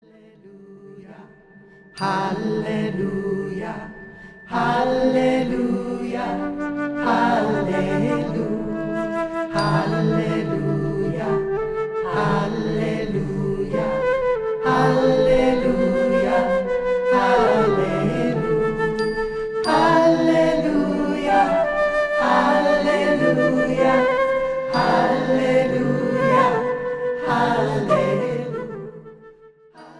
rich harmonies and instrumental textures
Trad. chant